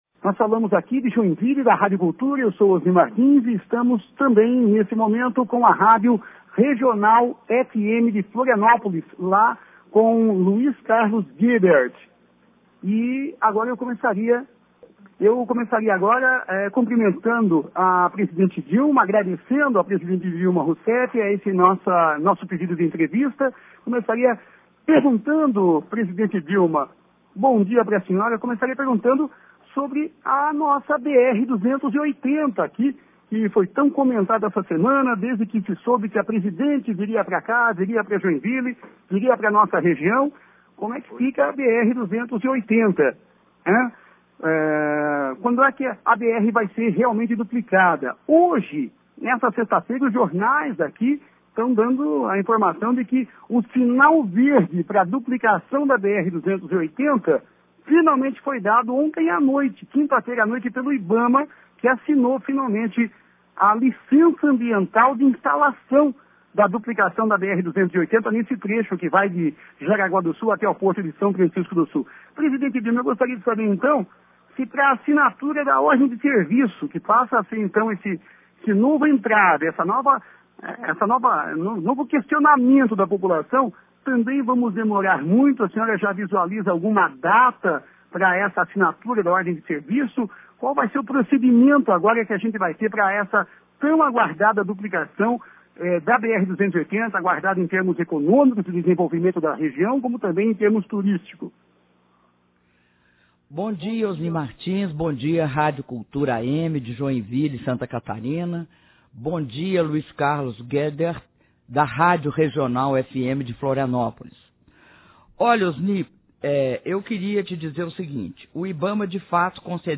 Áudio da entrevista concedida pela Presidenta da República, Dilma Rousseff, para as rádios Regional FM, de Florianópolis, e Cultura AM, de Joinvile - Palácio da Alvorada/DF